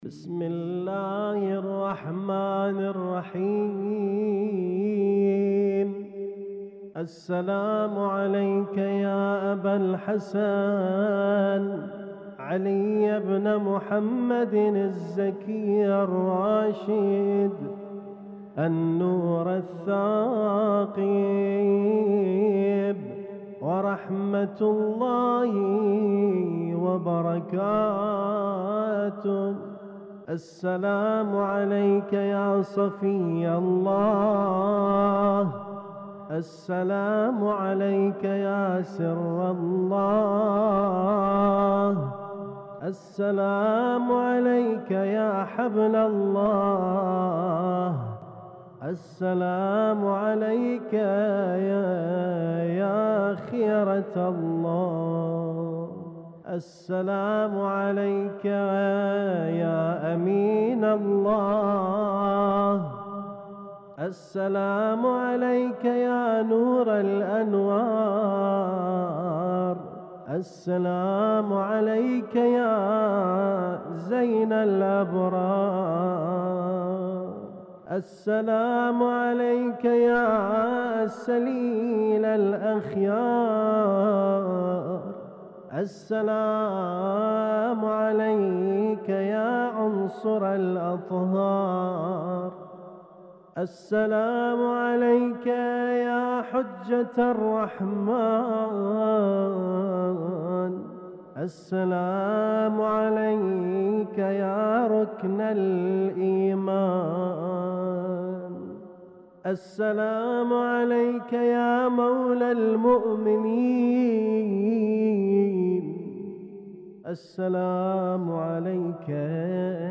اسم التصنيف: المـكتبة الصــوتيه >> الزيارات >> الزيارات الخاصة